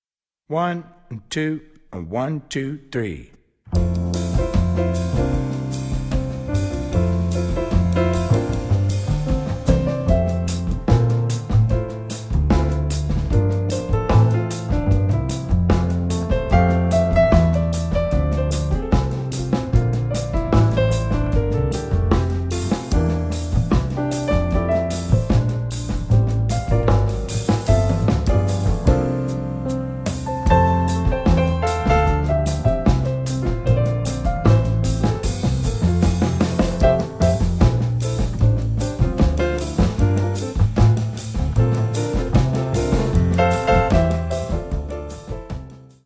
Voicing: Jazz Play-